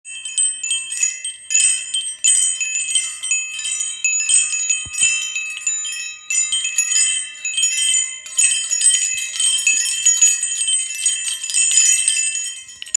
Venkovní zvonkohra 73cm
Kovovou zvonkohra s jemnými meditačními zvuky, které uklidňují a přinášejí pocit relaxace.
I jemný vánek rozpohybuje zvonečky a vykouzlí jemné tóny této zvonkohry.
Zvuky zvonkohry jsou nenápadné a přitom krásné, takže přirozeně zapadnou do ducha zahrady.